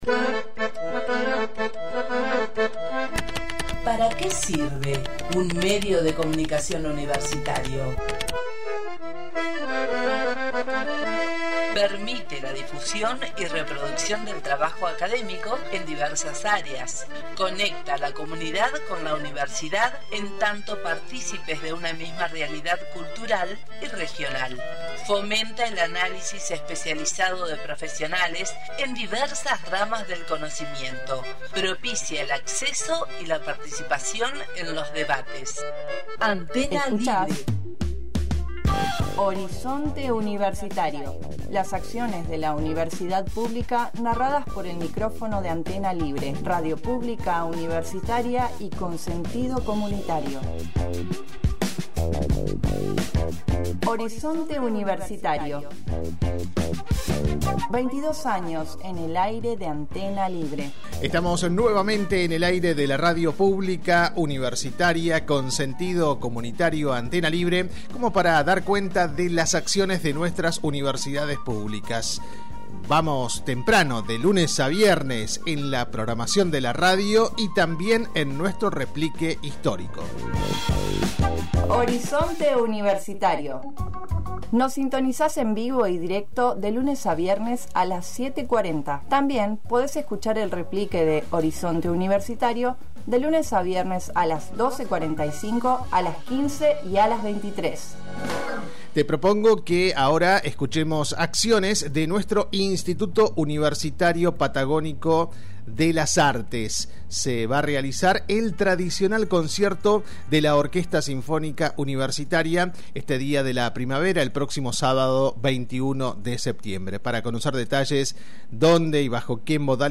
En H orizonte Universitario dialogamos con la profesora